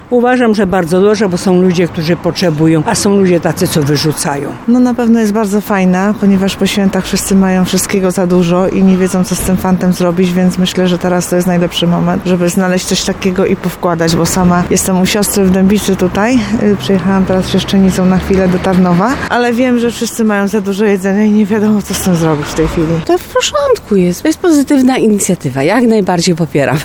27jadlodzielnia-sonda.mp3